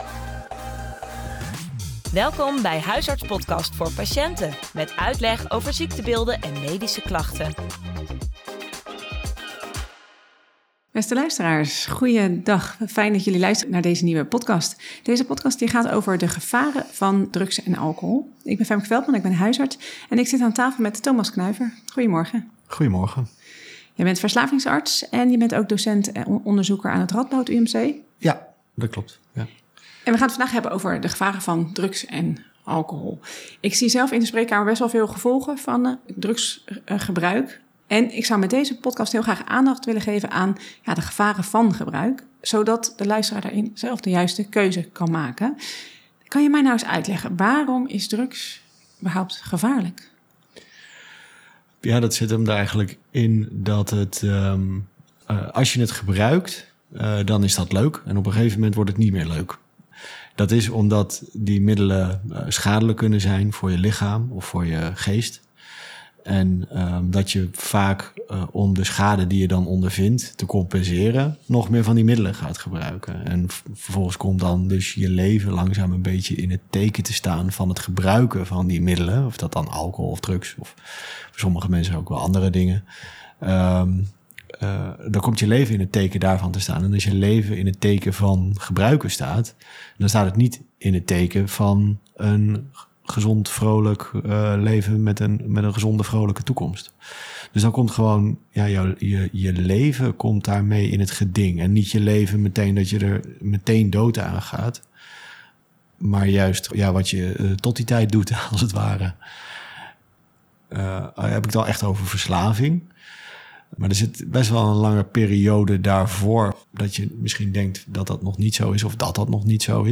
Een interview